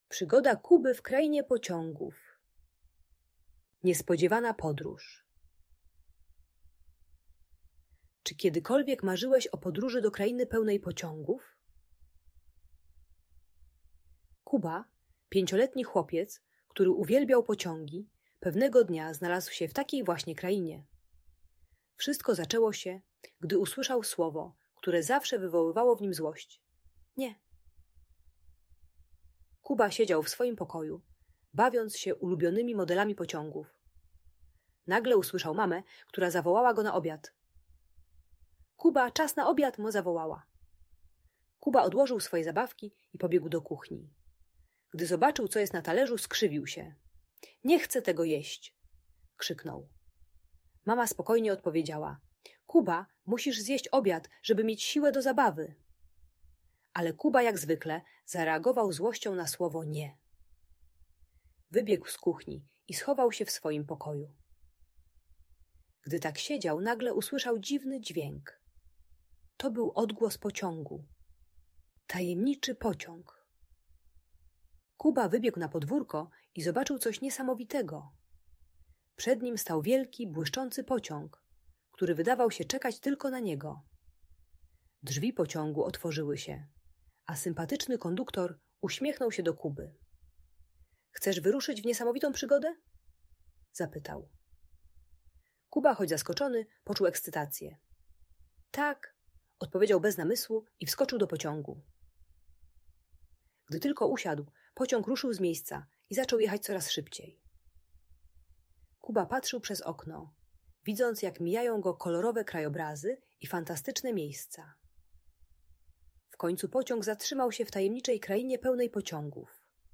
Przygoda Kuby w Krainie Pociągów - Audiobajka